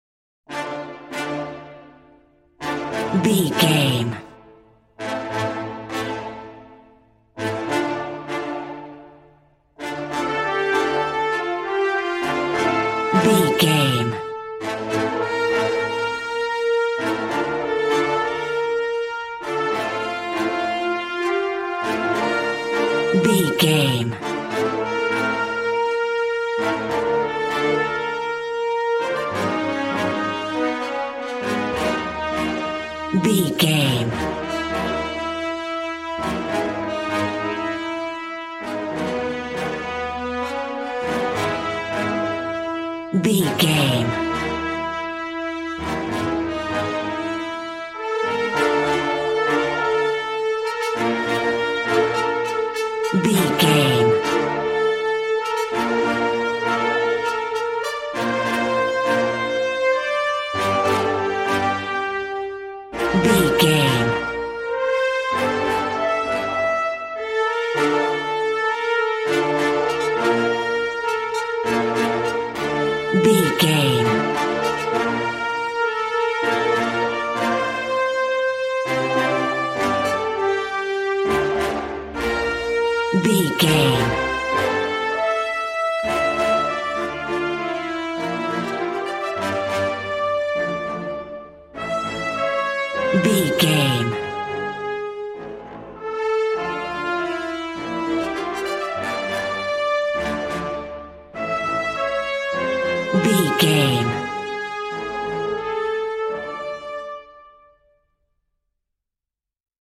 Regal and romantic, a classy piece of classical music.
Aeolian/Minor
regal
cello
double bass